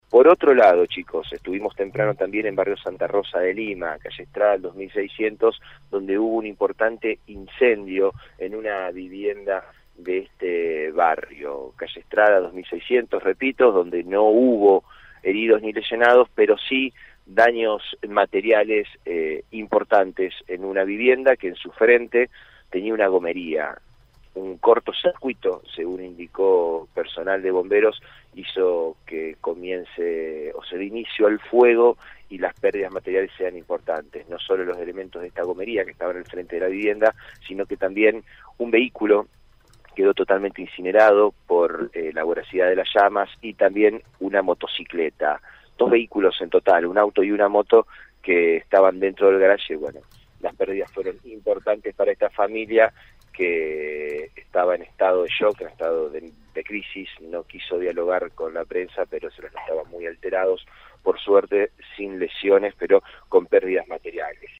AUDIO DESTACADOSanta Fe